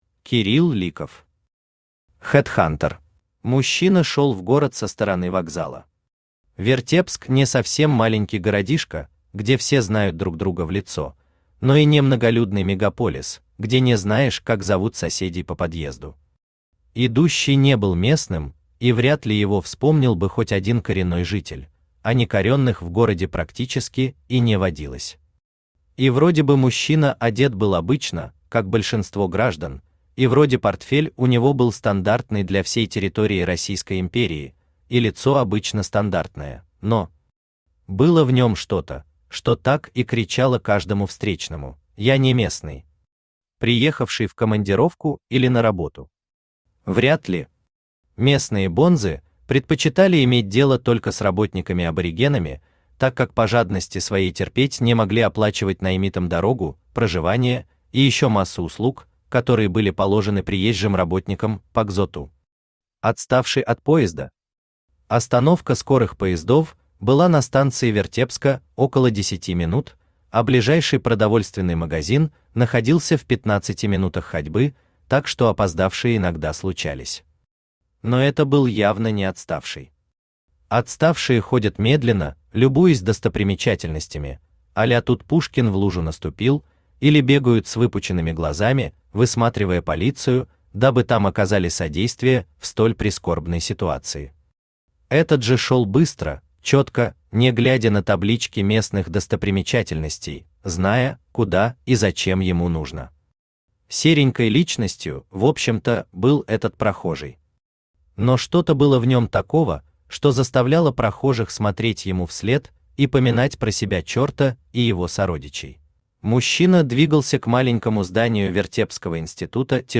Аудиокнига Хэдхантер | Библиотека аудиокниг
Aудиокнига Хэдхантер Автор Кирилл Ликов Читает аудиокнигу Авточтец ЛитРес.